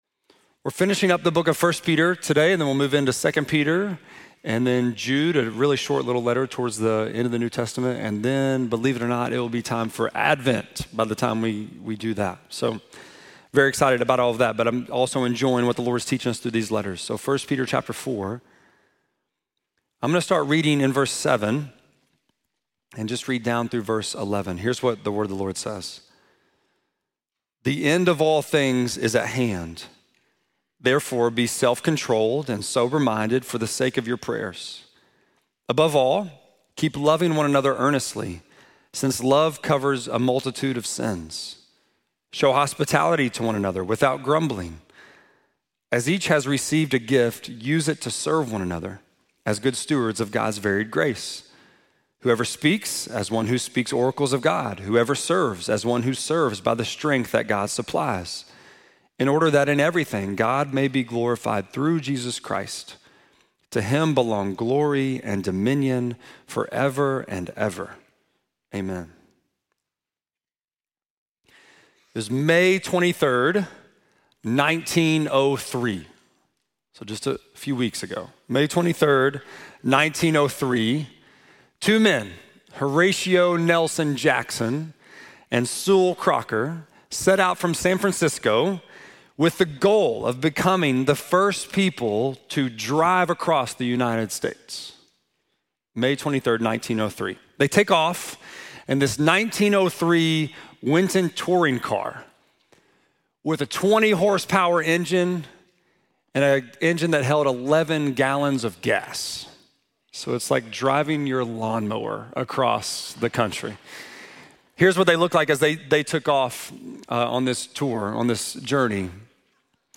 10.12-sermon.mp3